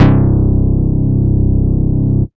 LOW135BASS-R.wav